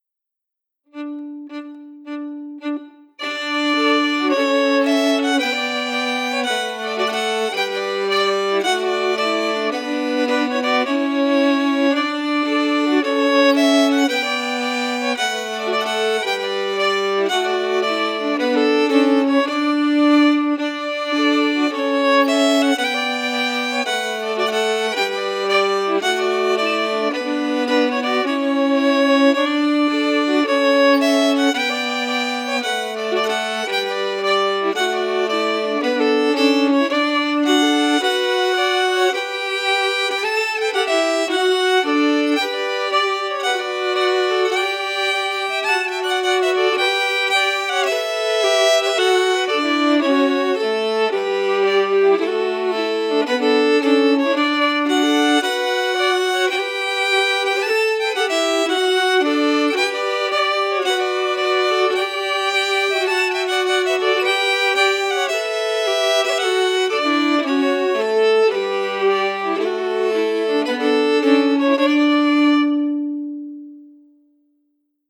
Key: D
Form: Strathspey
Harmony emphasis